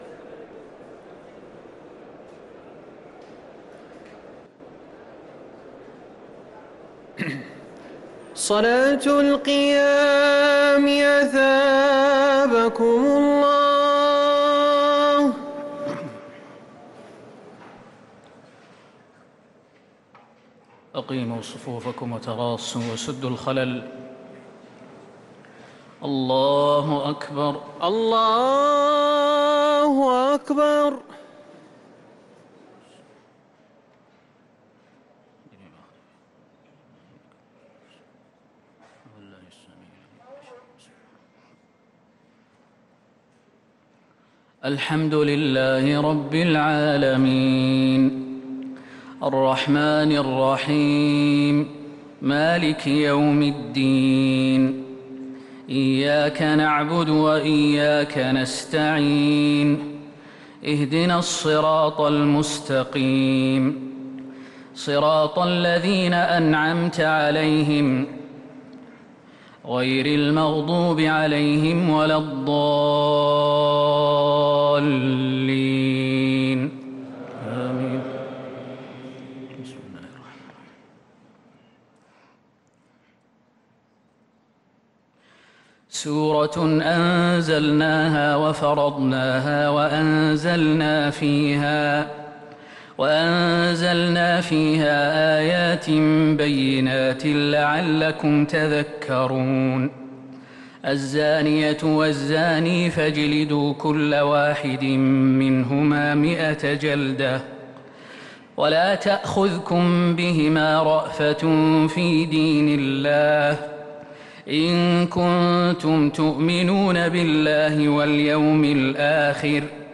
Ramadan Tarawih